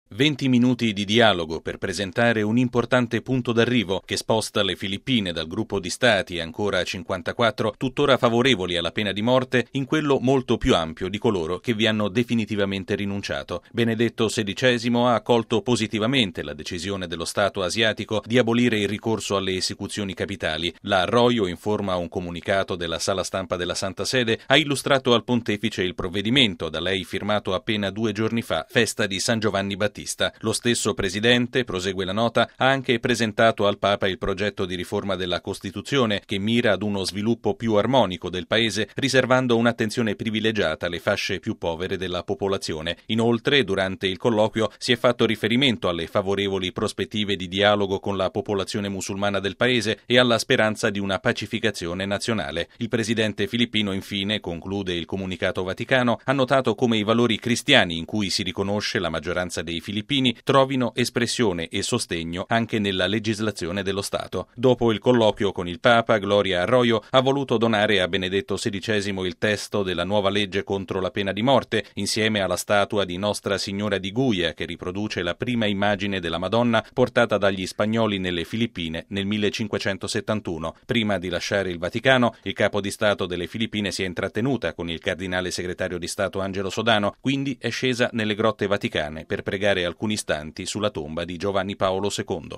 Sono gli argomenti che hanno animato l’incontro personale tra Benedetto XVI e il presidente delle Filippine, Gloria Macapagal Arroyo, ricevuta questa mattina in Vaticano insieme con il marito e il seguito. I particolari dell’udienza nel servizio